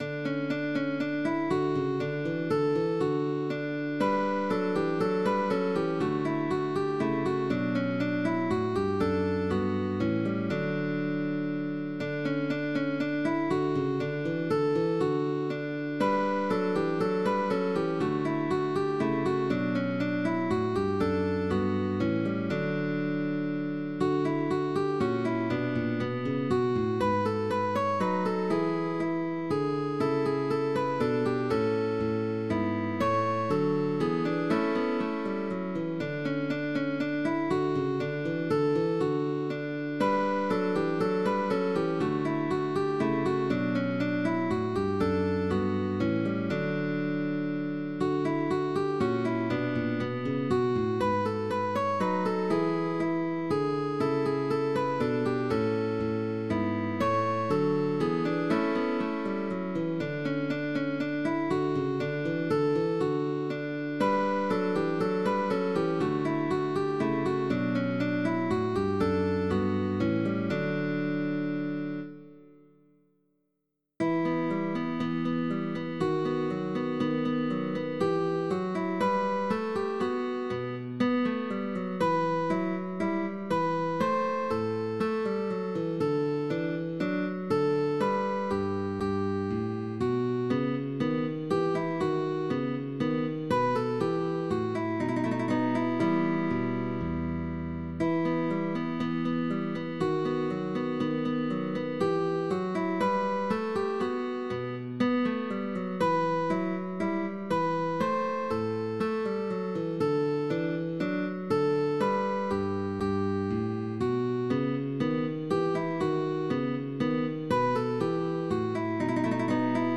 Guitar duo sheetmusic
Three Dances
Let’s play together (Pupil and Teacher)
Tag: Baroque